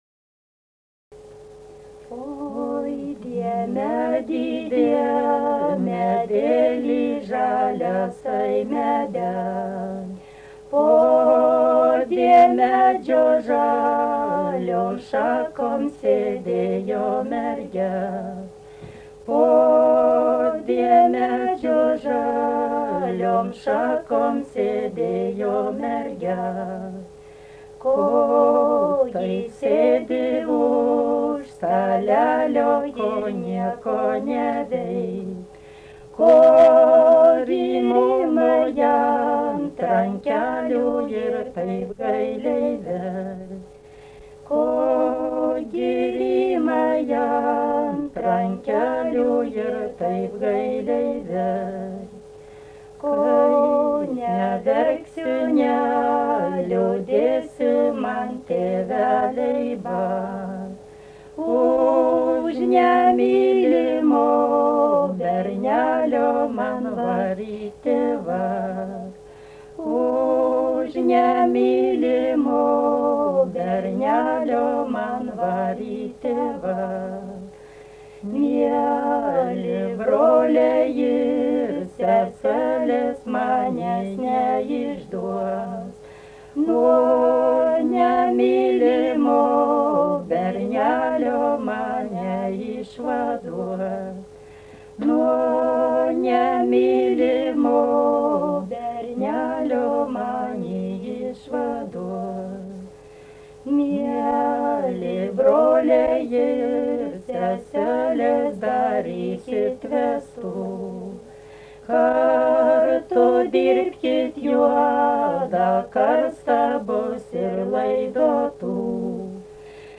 daina
Čypėnai
vokalinis